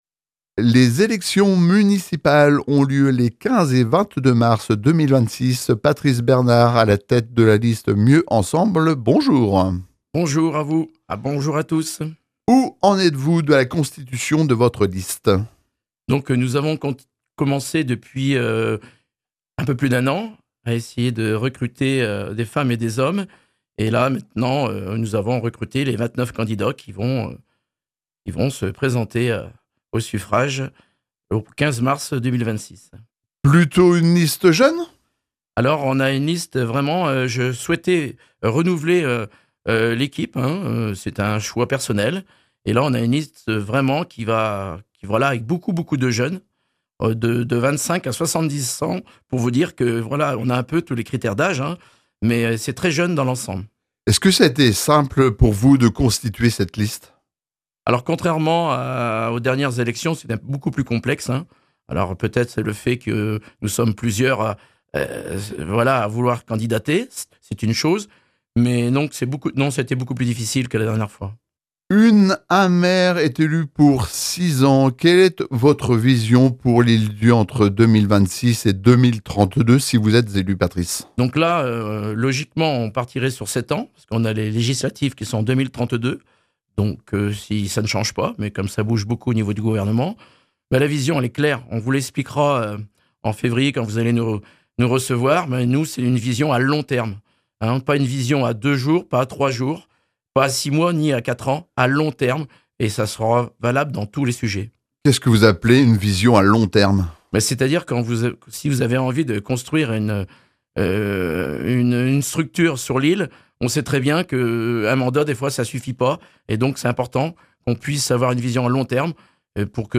Nous ouvrons aujourd’hui notre série d’interviews consacrées aux élections municipales des 15 et 22 mars 2026 à l’Île d’Yeu.